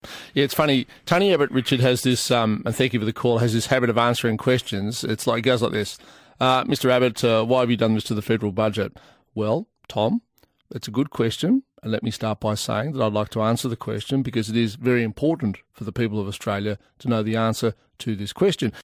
does his best Tony Abbott impersonation